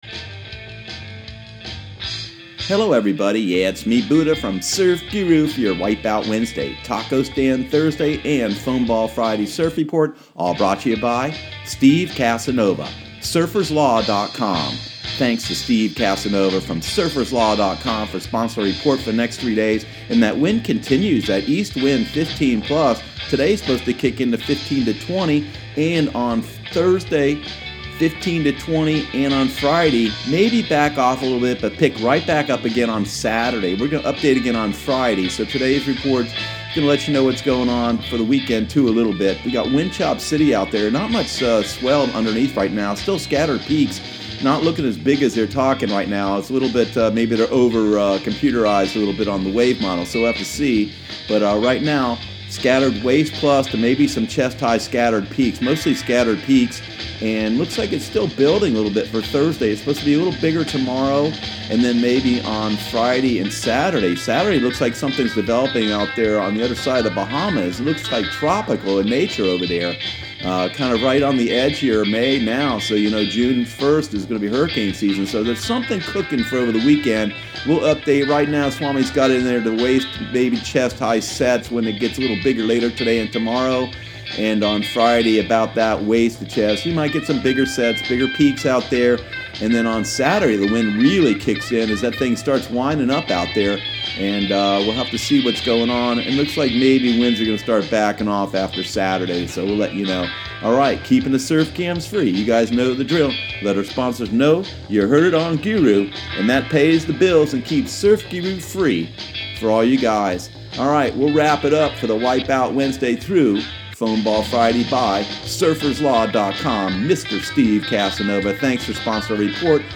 Surf Guru Surf Report and Forecast 05/02/2018 Audio surf report and surf forecast on May 02 for Central Florida and the Southeast.